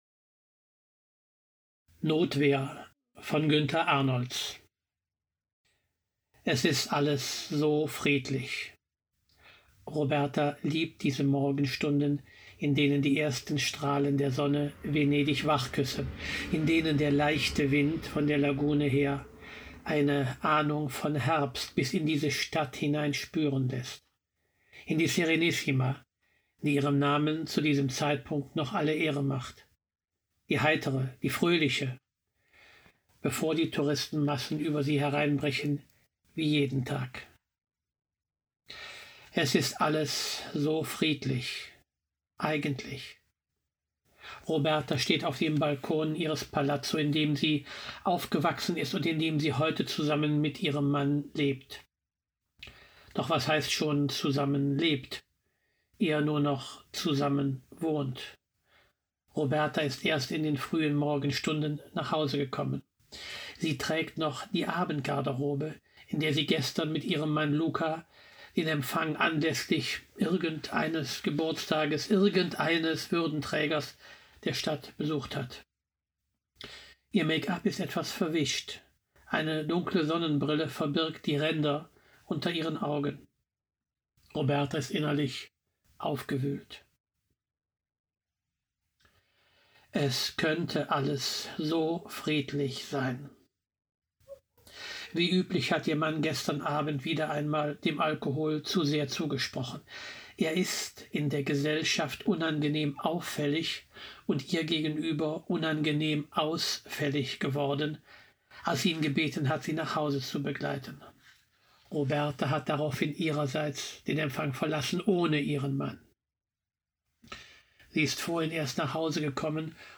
liest